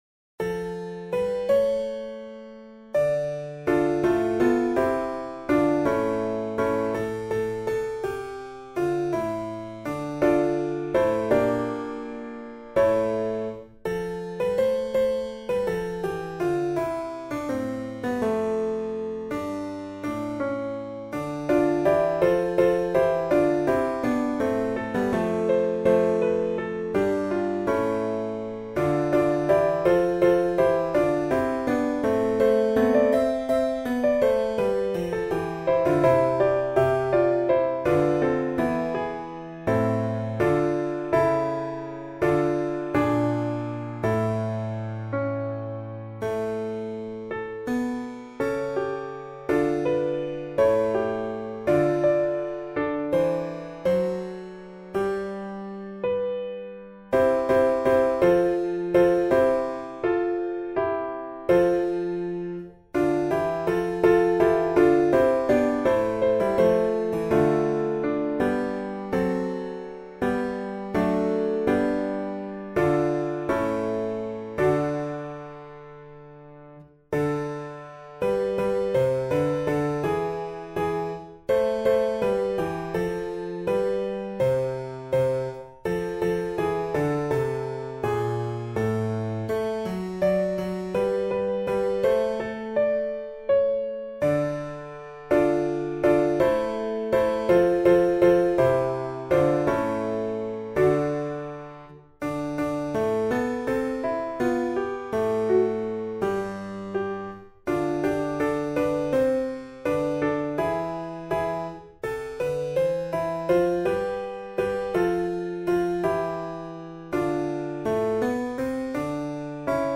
A 4 voces (Tiple I, II, Alto y Tenor)